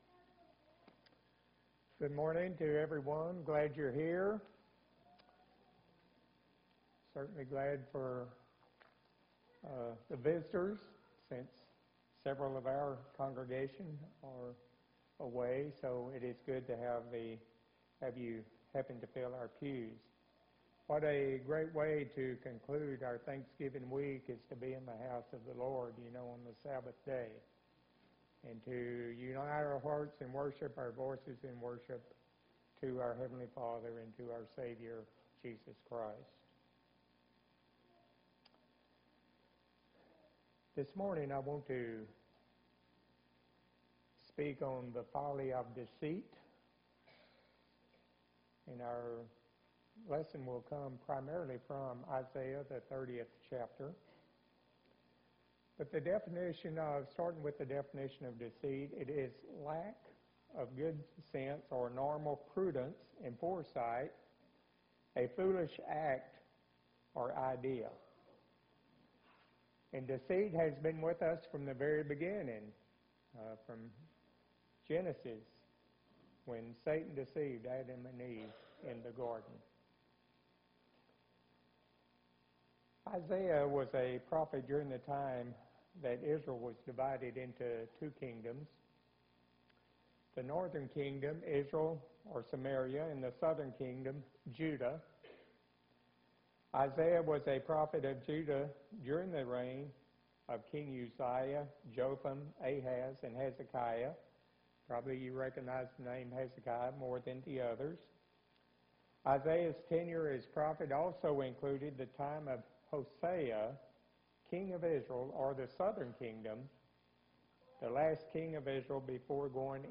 11-28-15 « sermon blog